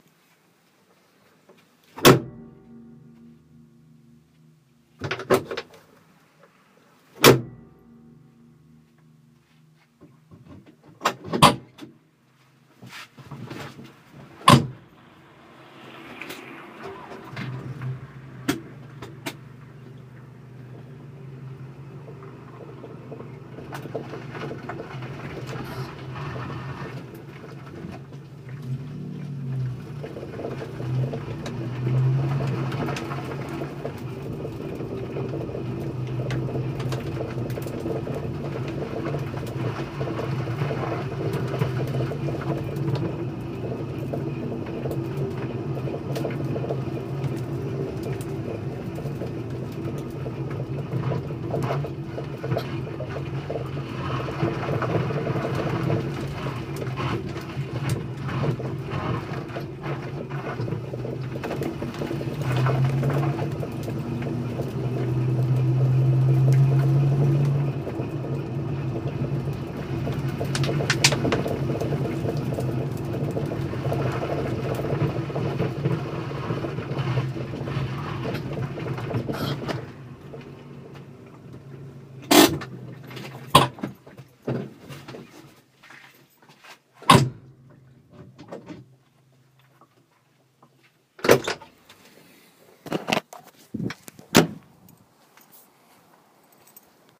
Oljud bakvagn.
Jo, jag har ett väldigt irriterande ljud någonstans ifrån bakvagnen. Ett slags klonkande/knackande ljud.
Tänk er att ni knackar lite lätt på plåten med handen, ungefär så låter det inuti bilen.
30 sek in i klippet så hörs det.
Kan också tillägga att detta ljud kommer alltså vid körning över säg 10km/h och det börjar låta mindre när över 50/60 km/h. Dvs i princip endast vid låg fart.
OBS. Det har inte med dåliga vägar att göra, i videoklippet så kör jag 50 meter på slät asfalt ifrån garageuppfarten och tillbaka.